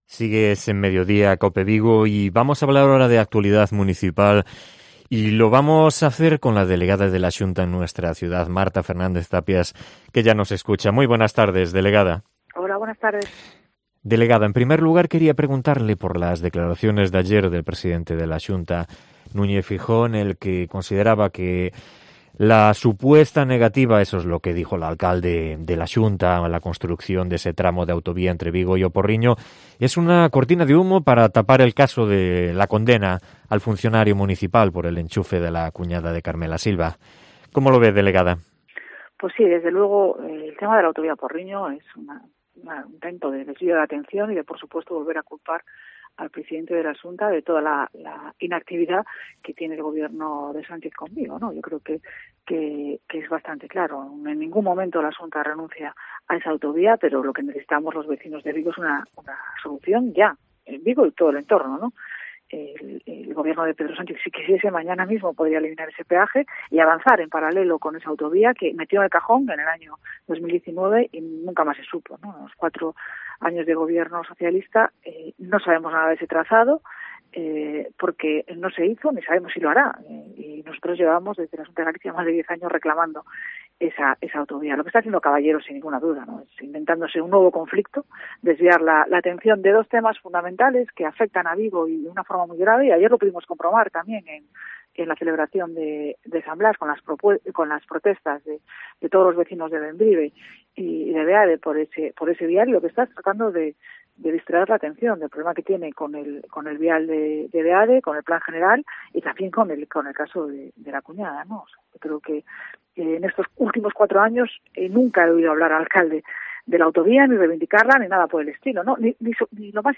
Entrevista a Marta Fernández-Tapias, delegada de la Xunta en Vigo